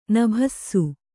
♪ nabhassu